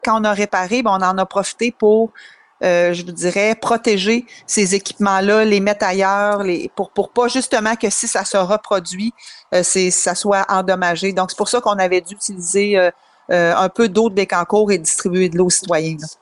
La mairesse a assuré que la Ville a tiré des leçons du printemps dernier.